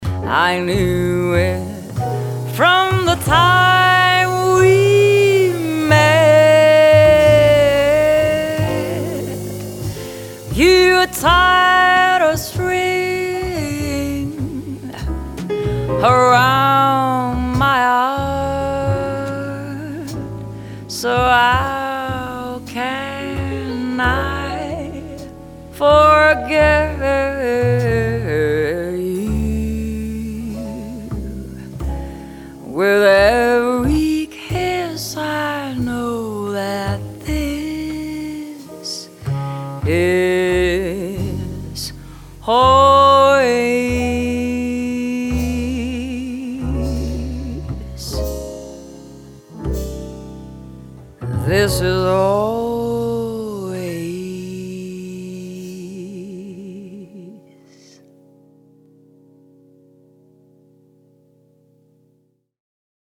Vocalist
Grand Piano
Double-Bass
Drums
Guitar
Violin
Trombone